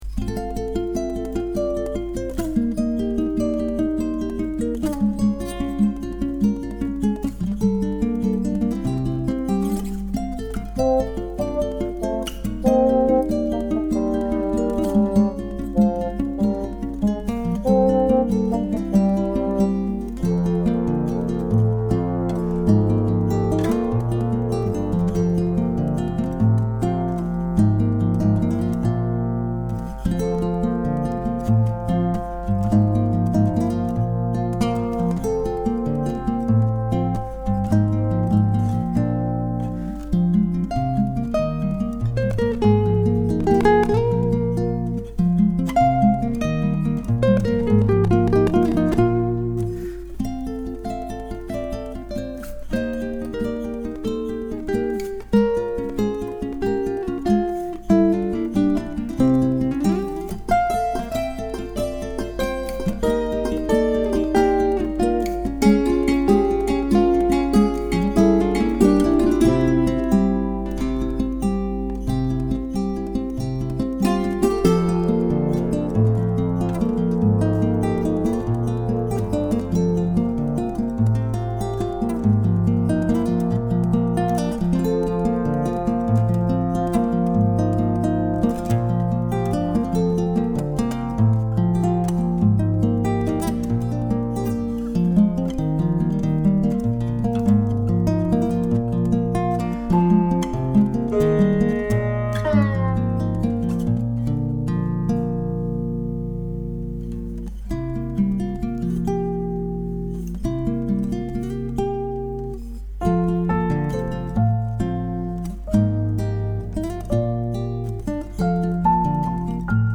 Y otra melodía con un poquito de orquestación. No demasiada porque se nota bastante que se trata de instrumentos software…(las guitarras no, por supuesto 😀 )